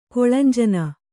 ♪ koḷanjana